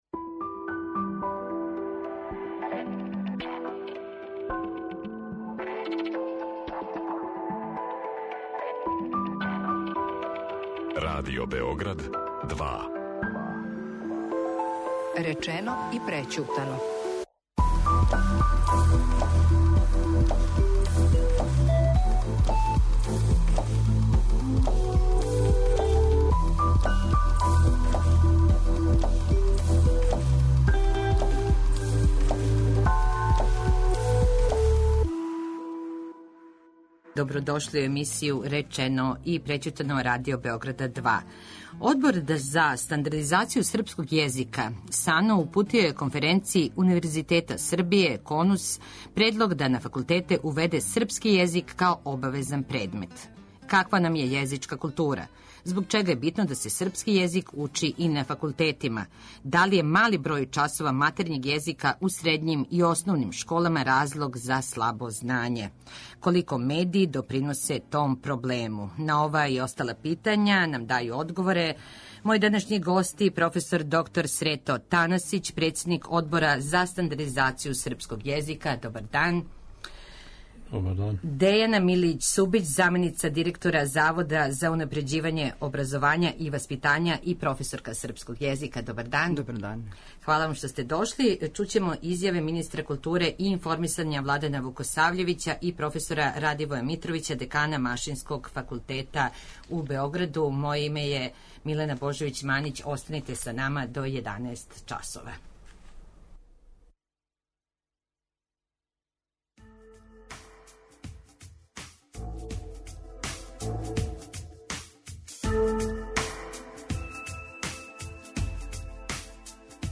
[ детаљније ] Све епизоде серијала Аудио подкаст Радио Београд 2 Жорж Бизе: Друга симфонија Властимир Трајковић Бака Надине крофне Дороти Дилеј, виолински педагог Георг Фридрих Хендл